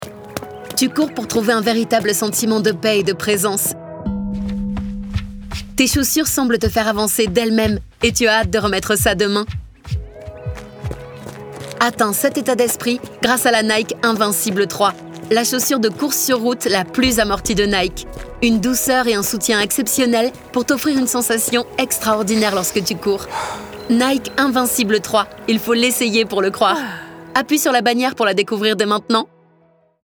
Female
Bright, Friendly, Versatile
STREET : URBAN : SLAM : POETIC - FILAB.mp3
Microphone: TLM103